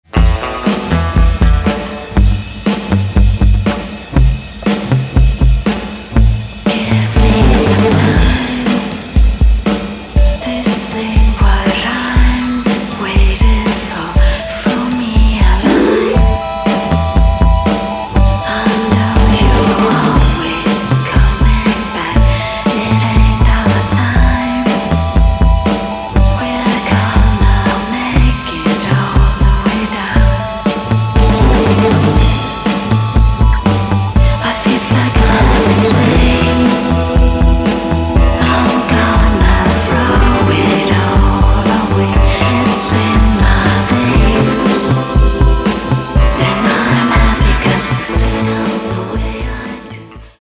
analoge Keyboards, Synthies und Effektgeräte
klassischem 60s Frauengesang